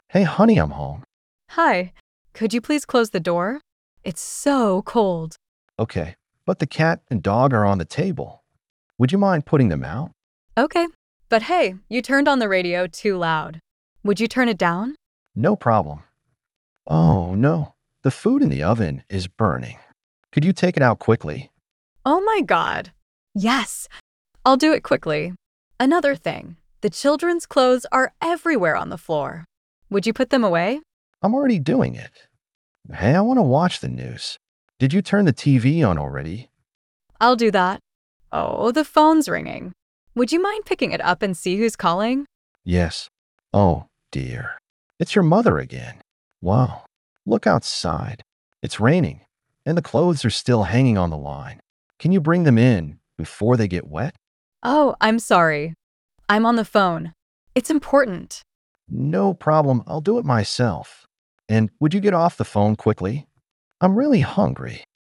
Husband-wife-phrasal-verb-role-play-2024.mp3